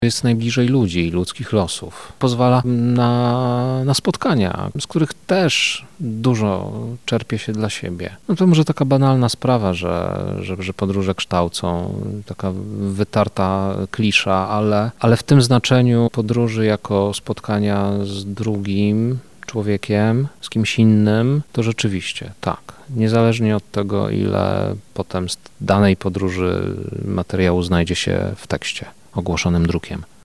Trwa drugi dzień Festiwalu Reportażu w Radiu Lublin.